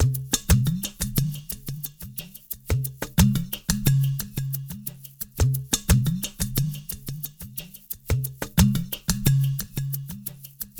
SMP PERCMX-R.wav